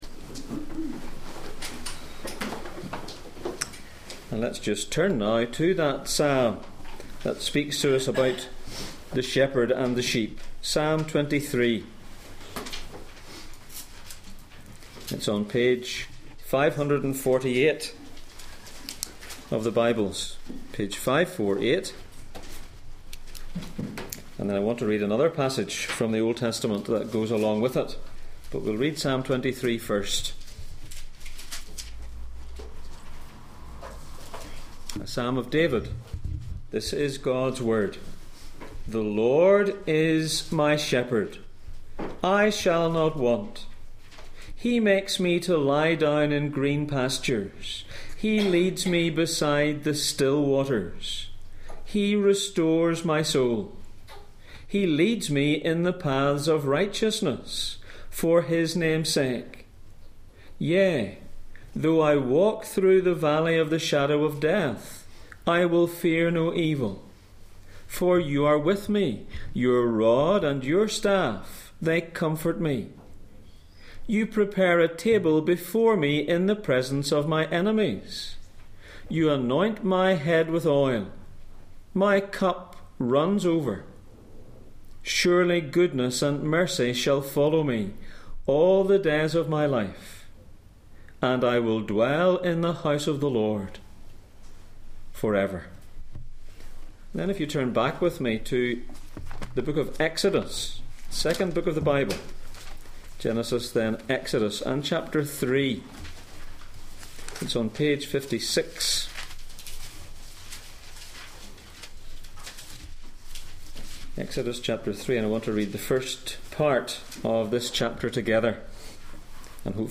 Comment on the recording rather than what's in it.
The Good Shepherd and His Sheep Passage: Psalm 23:1 Service Type: Sunday Morning %todo_render% « On which side of the gate are you?